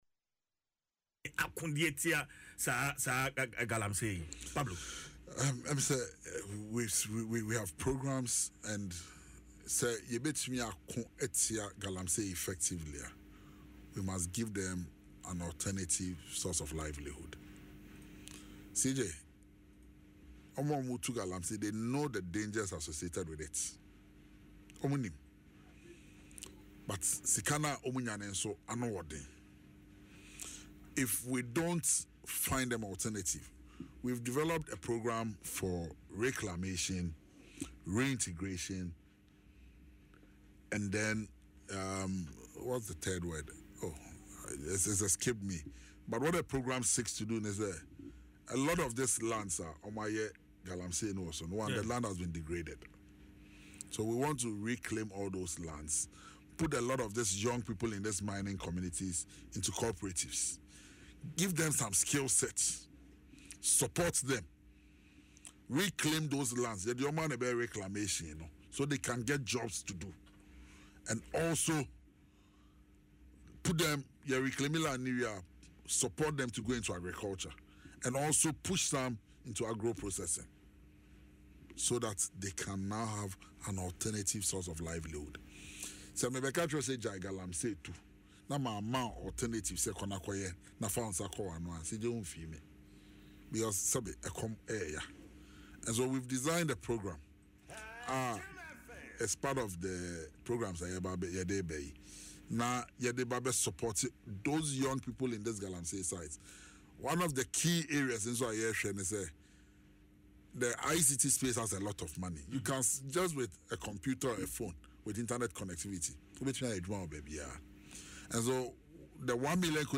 Speaking in an interview on Adom FM’s Dwaso Nsem, Opare Addo acknowledged that the fight against galamsey, like any other battle, can be won—but only through a critical and sustainable approach.